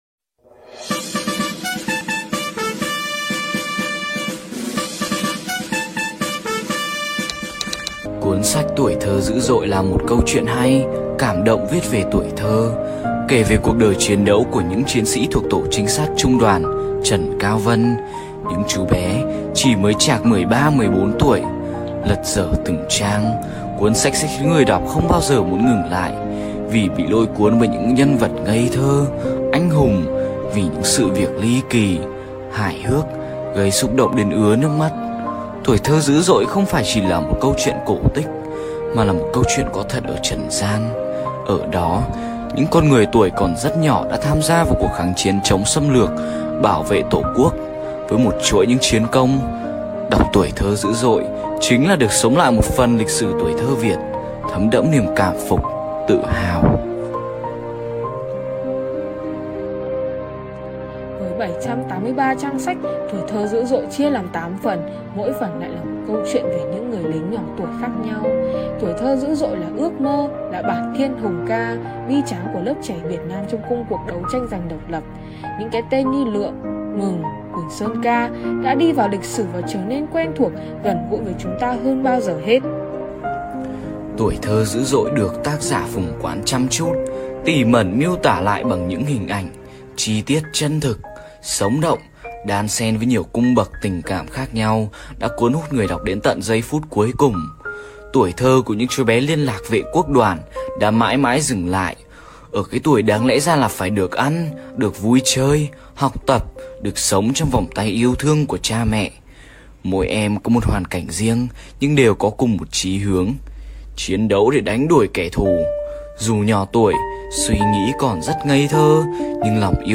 Sách nói | Gts: Tuổi thơ dữ dội